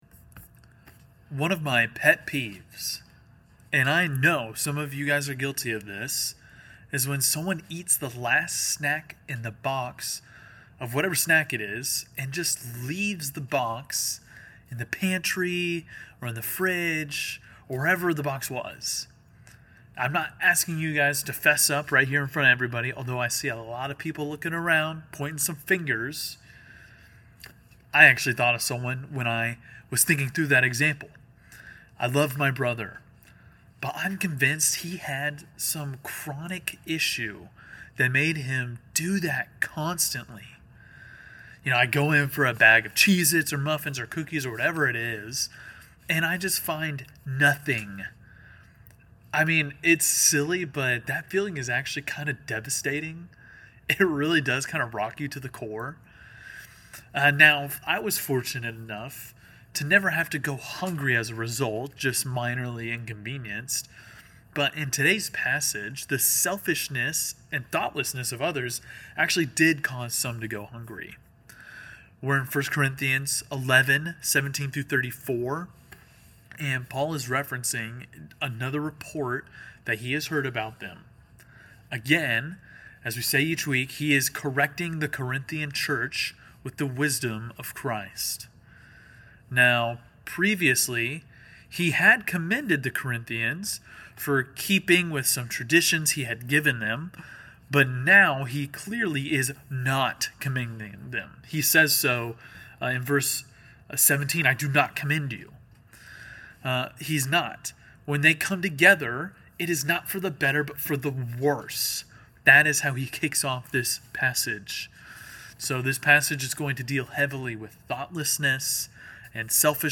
preaches through 1 Corinthians 11:17-34.&nbsp